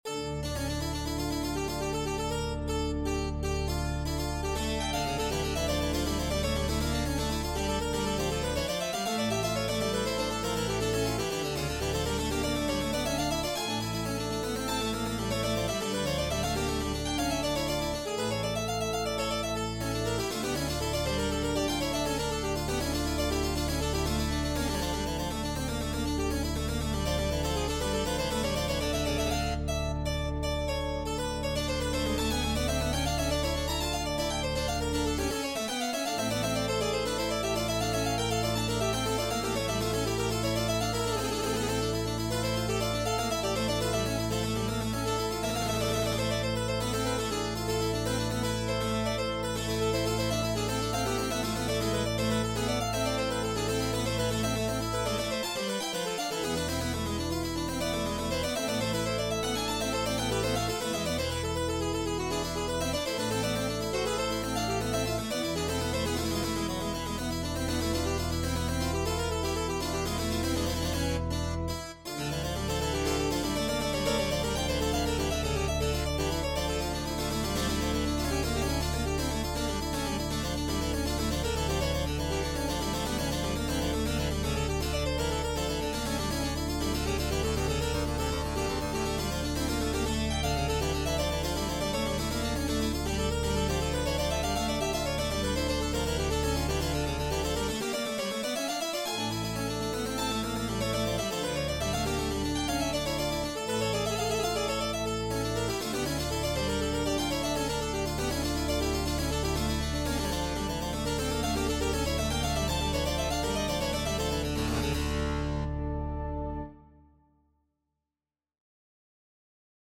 次の、”フーガの技法”の中から、４小節遅れでぴったりと追随する（潜在意識に隠れた追憶感を蘇らせるかのようなクオリアが素晴らしい）カノンも小節数を揃えるとよくわかる。足鍵盤遊び用に通奏低音（ドローン）を付加。その場でPC上で鳴らして試すことができるのも楽しい。
チェンバロとオルガンで。